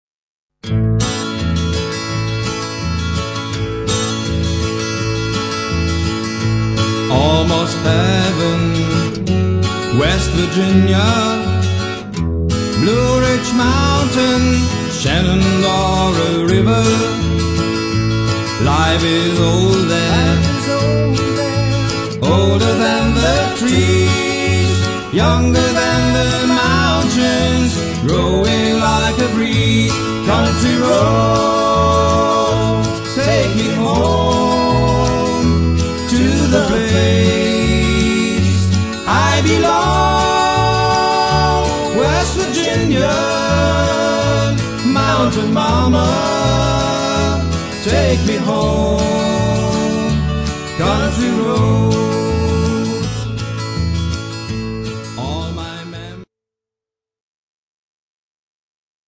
Ich singe immer live zur Gitarre.
Ein Rhytmusgerät begleitet meine Musik.
• Unplugged